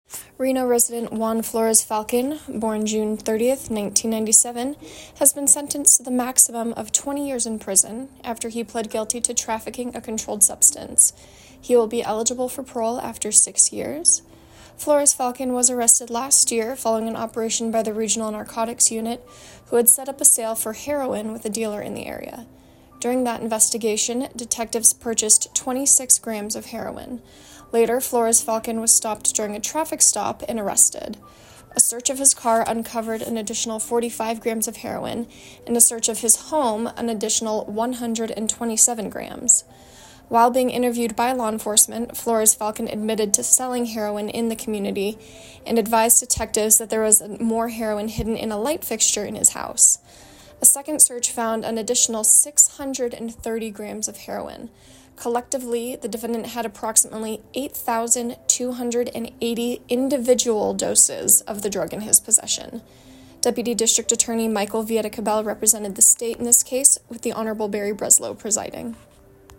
A recording of the press release for media can be heard below.